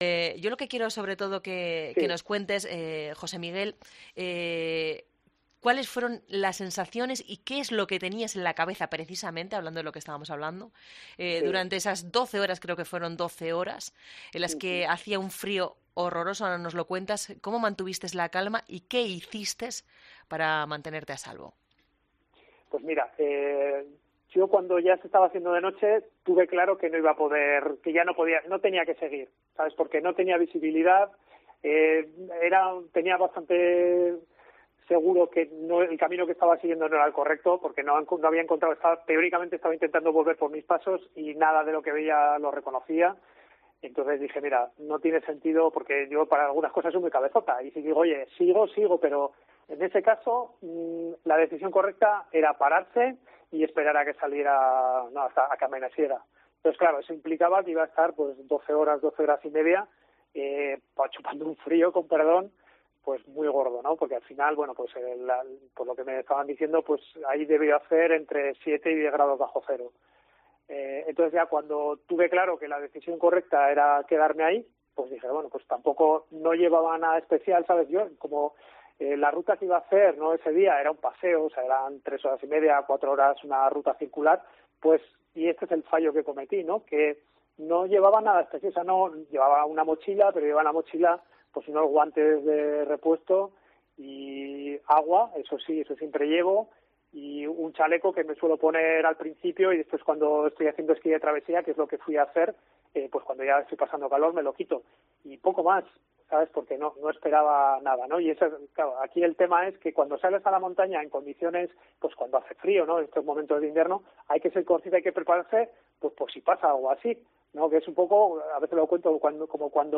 Así ha relatado esta angustiosa experiencia en COPE:
Ahora relata su experiencia con esa misma tranquilidad y con una sonrisa encubierta en cada palabra.